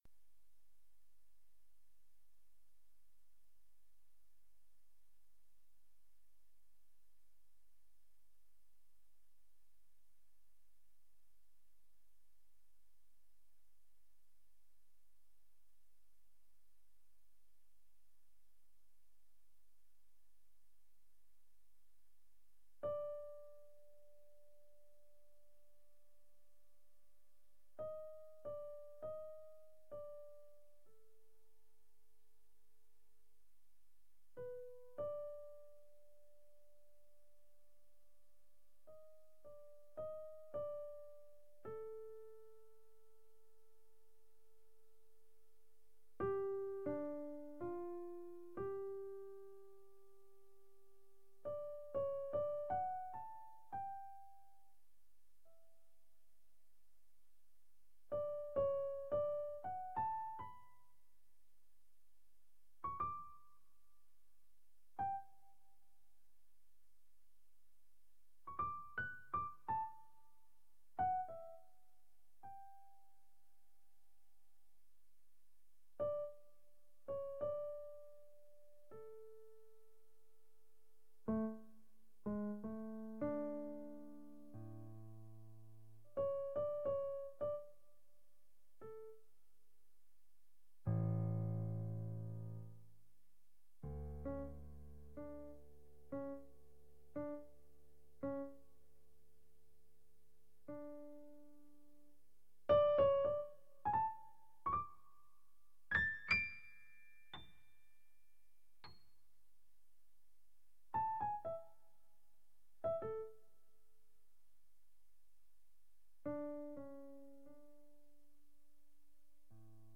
Vor mir das Klavier und ich überlege welche Töne es heute am liebsten mag.
Eine völlig freie, spontane Improvisation inspiriert ausschließlich durch eine Schneeameisenschau.
Die Aufnahme(n) wird/werden ungeschnitten so gelassen.
schneeameisenschau durch mich selbst gefiltert und in musik gepackt selbstreflektierend zu beurteilen ist schwierig soviel steht aber fest: es war nichts geplant.
erster titel als .ogg mußte ich eher an eine einzelne Schneeameise denken die da so rumschlendert während es beim 2.titel wohl mehrere waren die mich anguckten.